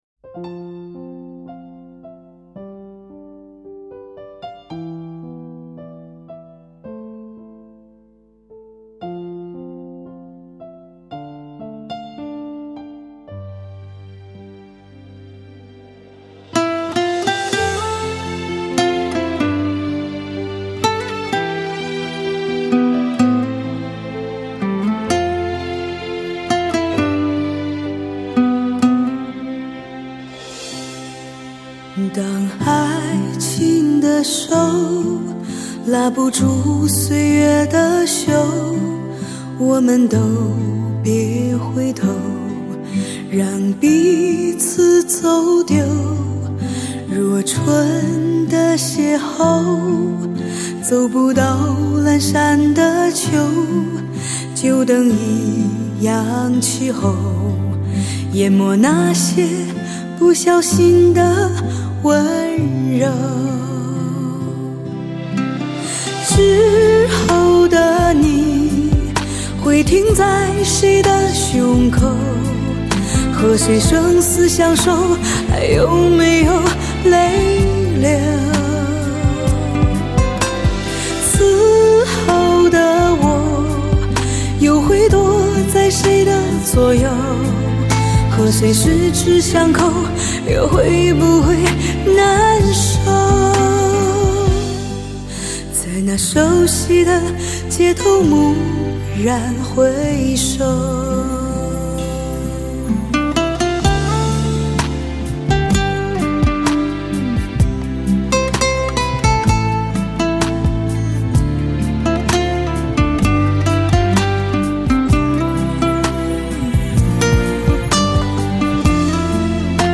磁性的嗓音吸附你心灵深处
用心的演唱诉说真挚的情感